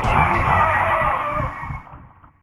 PixelPerfectionCE/assets/minecraft/sounds/mob/horse/zombie/hit4.ogg at c12b93b9c6835a529eb8ad52c47c94bf740433b9